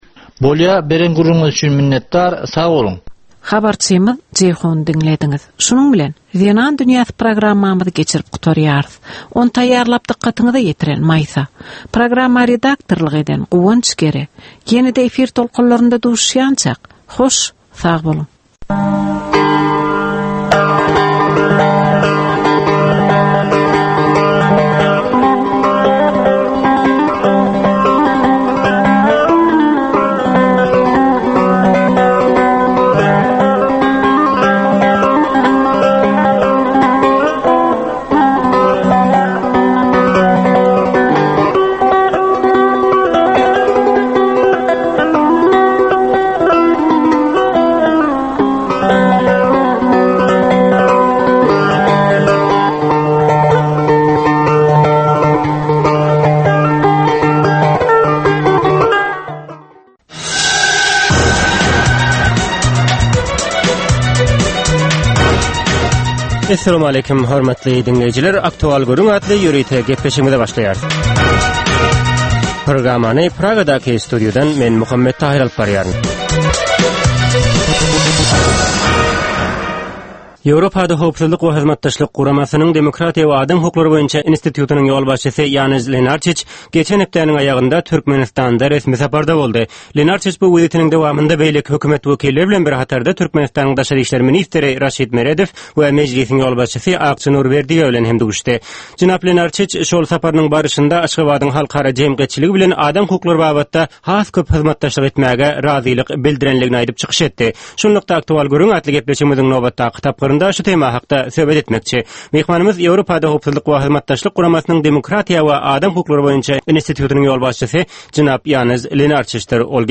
Hepdäniň dowamynda Türkmenistanda ýa-da halkara arenasynda ýüze çykan, bolup geçen möhüm wakalar, meseleler barada anyk bir bilermen ýa-da synçy bilen geçirilýän ýörite söhbetdeşlik. Bu söhbetdeşlikde anyk bir waka ýa-da mesele barada synçy ýa-da bilermen bilen gürrüňdeşlik geçirilýär we meseläniň dürli ugurlary barada pikir alyşylýar.